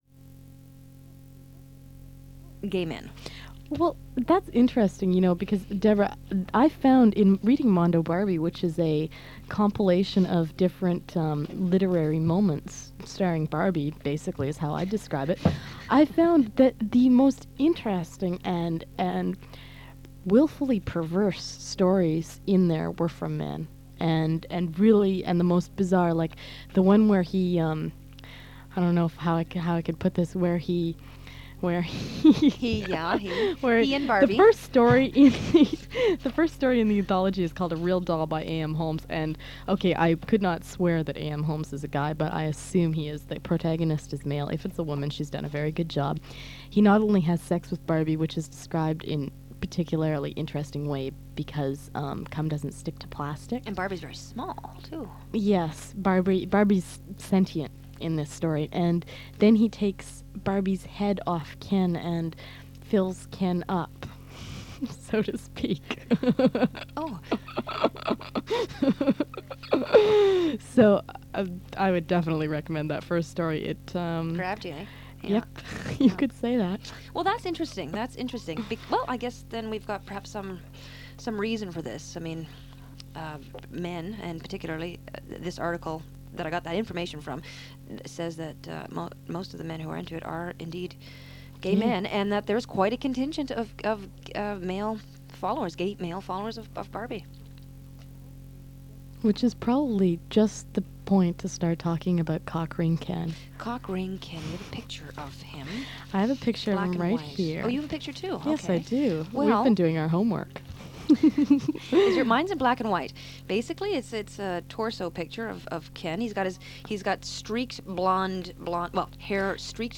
The Dykes on Mykes radio show was established in 1987.